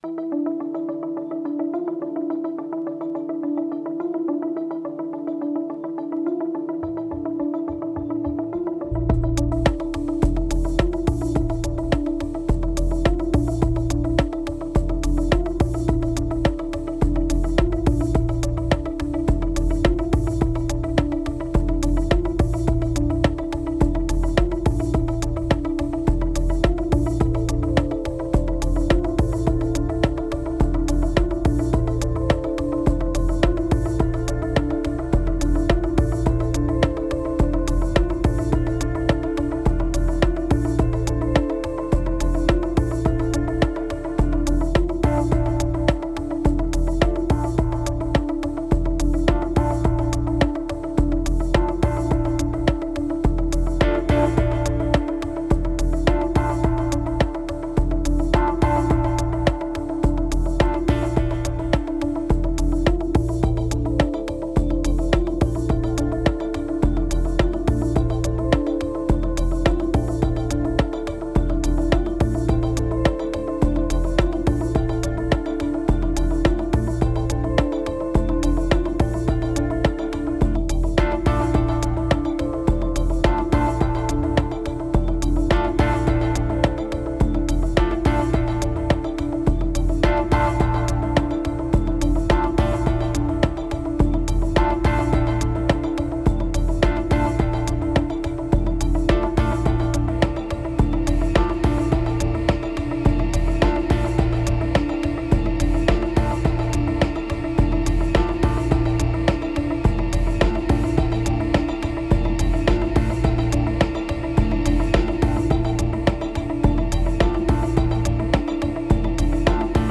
928📈 - 90%🤔 - 106BPM🔊 - 2025-03-27📅 - 1064🌟
Unsampled beat, first take.